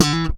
ALEM FUNK E4.wav